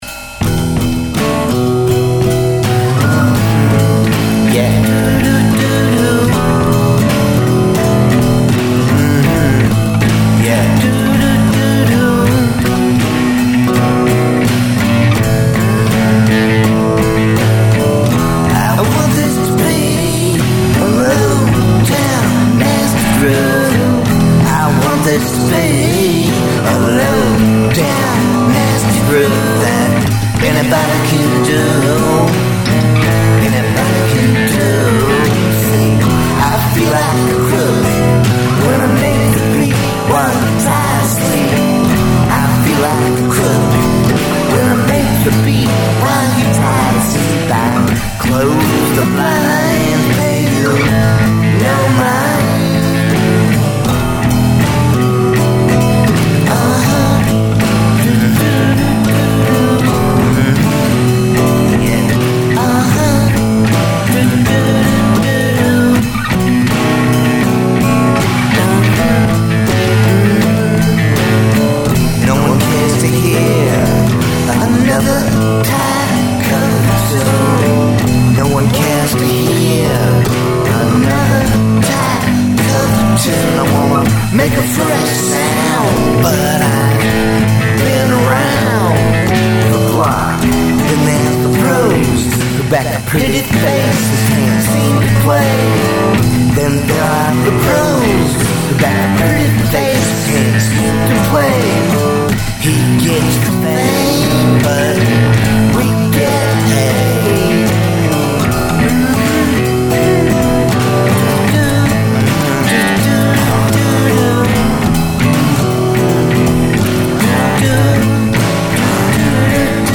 So pleased to say that I played all the instruments,
I am having so much fun getting to play my new drum set.